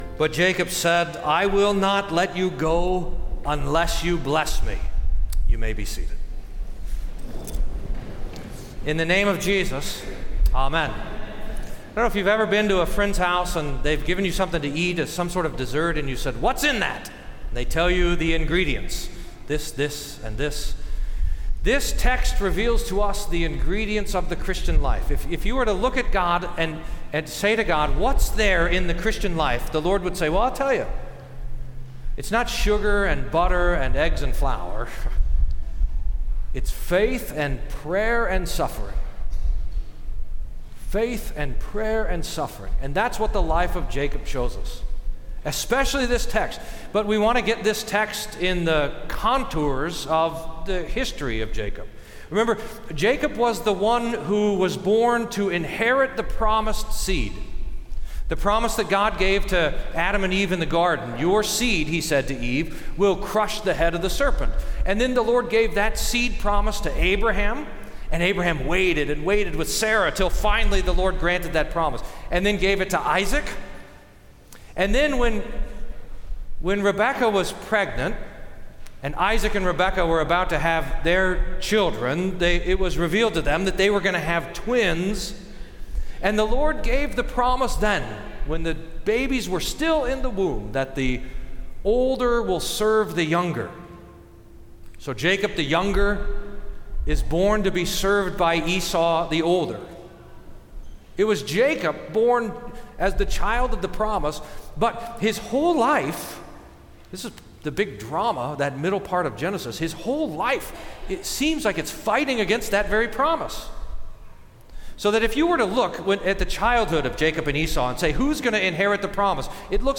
Sermon for Nineteenth Sunday after Pentecost